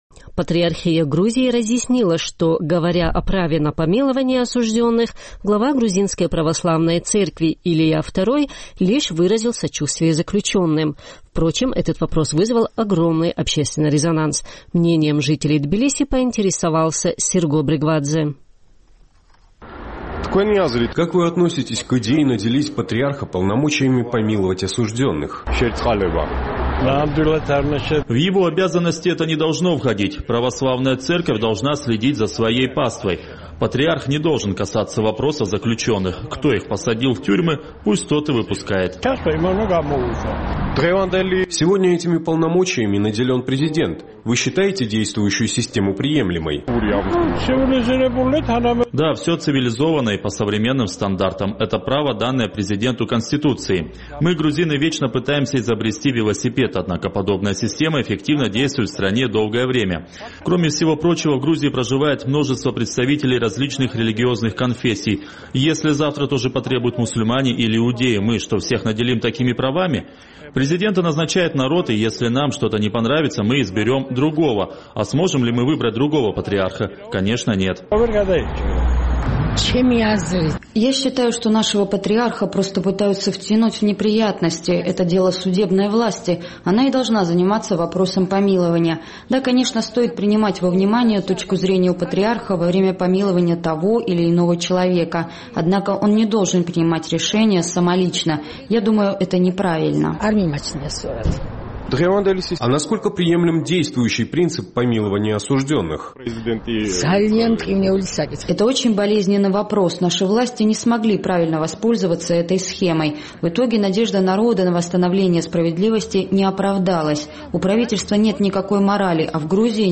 Наш тбилисский корреспондент поинтересовался мнением жителей грузинской столицы по поводу права патриарха на помилование осудженных.